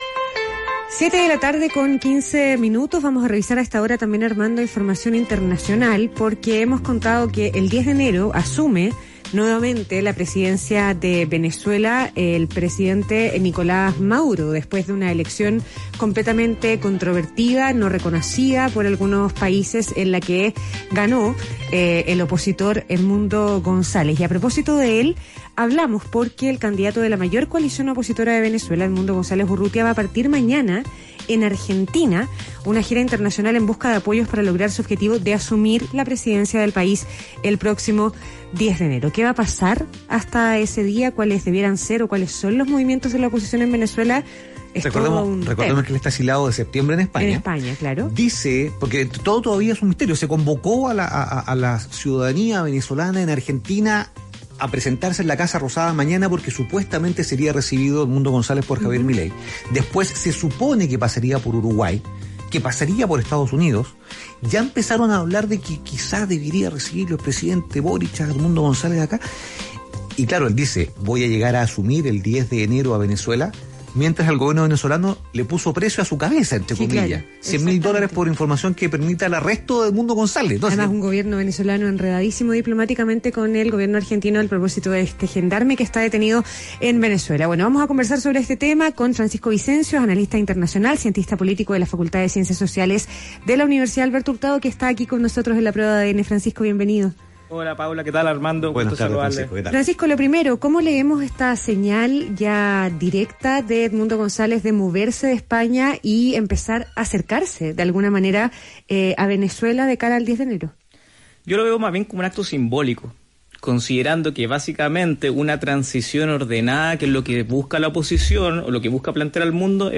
Fuente: ADN Radio